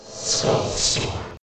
skull_storm.ogg